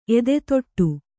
♪ edetoṭṭu